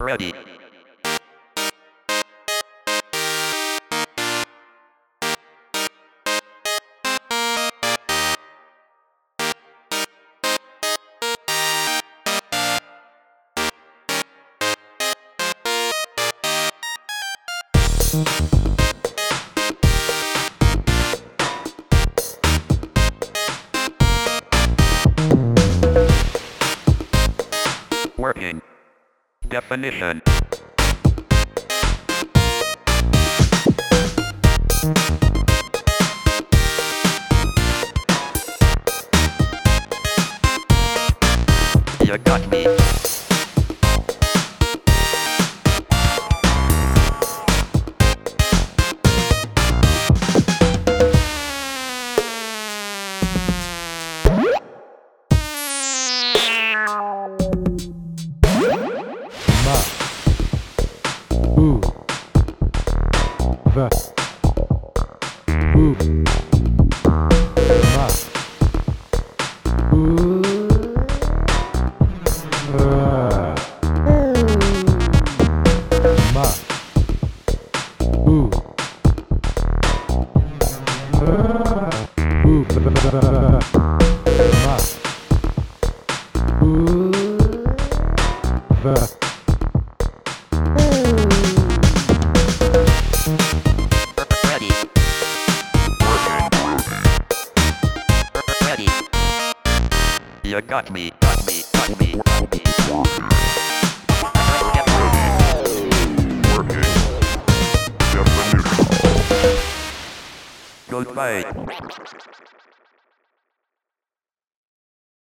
A happy track in D major (ish). I didn't tune the beep, so it's based on however close to D it is. Features a bass sound made from a processor interrupt, a stock Move kit, an imported Alesis SR16 sample pack, and lots of fun with automation.
I sampled the really dirty pulse wave it produces in test mode, as well as some common phrases. This was all made with just three tracks, because I couldn't think of what to do with a fourth one. It's pretty busy as-is.